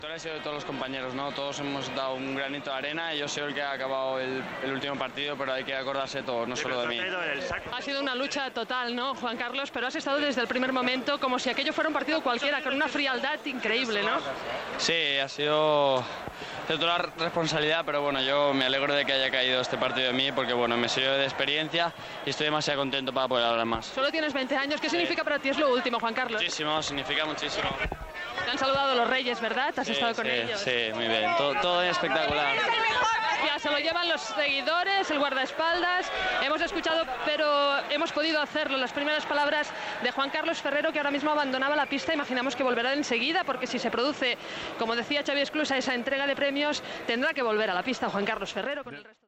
Espanya guanya per primera vegada la Copa Davis, entrevista al tennista Juan Carlos Ferrero
Esportiu